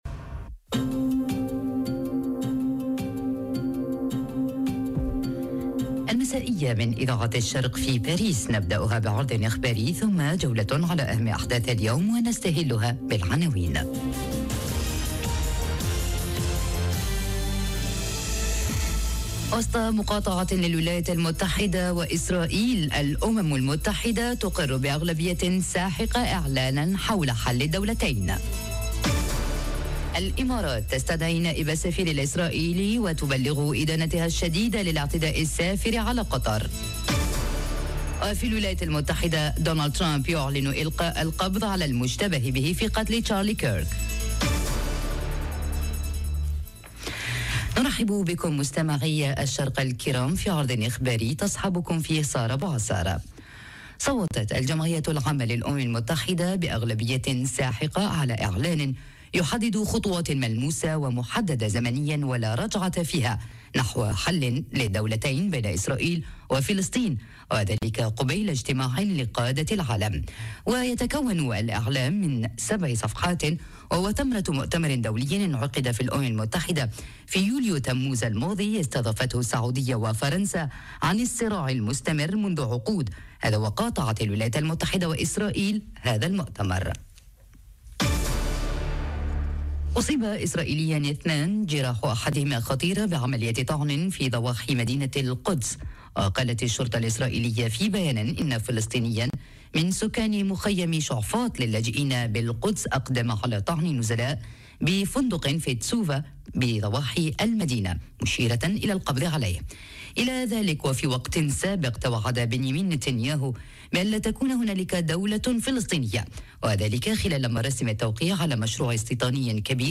نشرة أخبار المساْء: الإمارات تستدعي السفير الإسرائيلي، والأمم المتحدة تقر بأغلبية ساحقة إعلانا حول حل الدولتين - Radio ORIENT، إذاعة الشرق من باريس